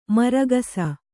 ♪ maragasa